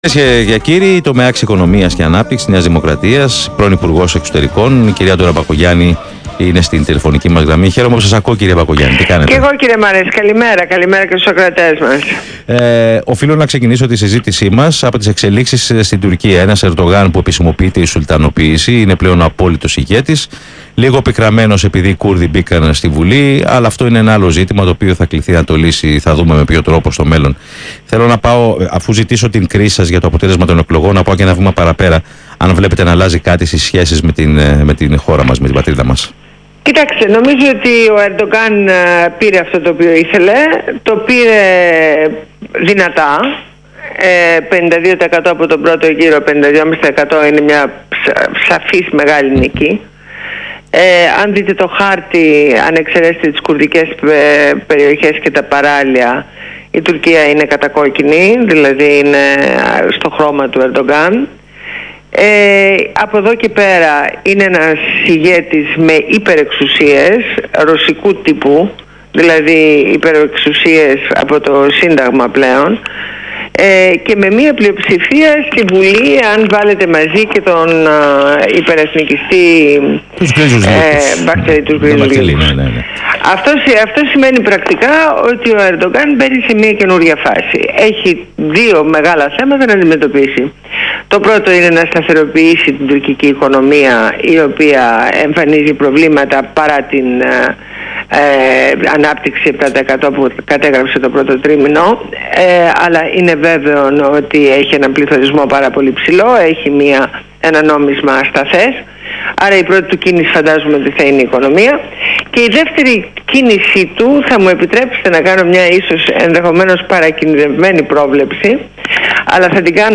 Συνέντευξη στο ραδιόφωνο του "ALPHA"